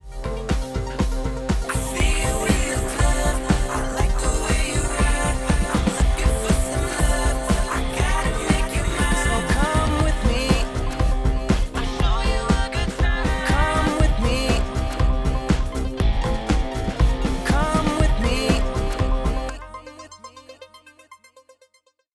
以下の例では、6つのバスコンプレッサーを2つの異なる曲で使用しています。
よりスムーズなサウンドのフィードバックコンプレッションタイプを使用しました。
推力をノーマルに設定し、トランジェントを維持するために最も遅いアタック、30を選択し、リリースは50msの比較的遅いものを選択しました。
一貫してトランジェントにスナップを加え、スネアをパワフルに前進させてくれます。